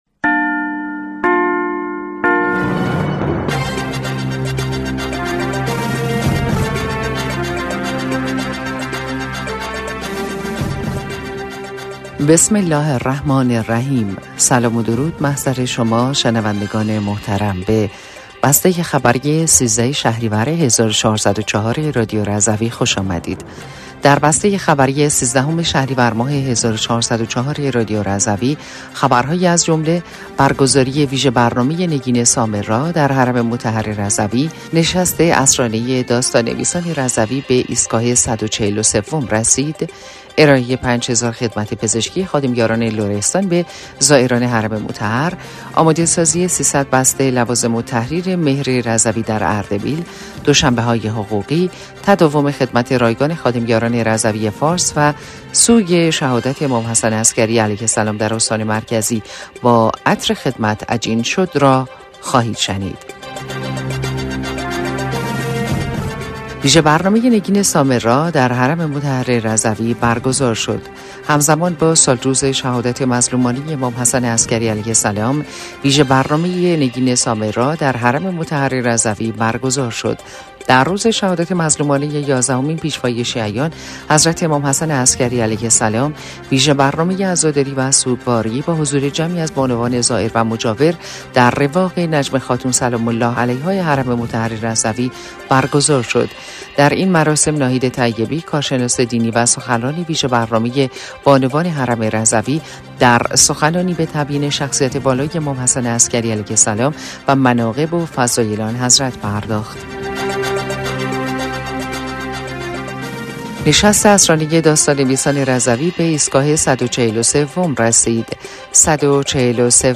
بسته خبری ۱۳ شهریور ۱۴۰۴ رادیو رضوی/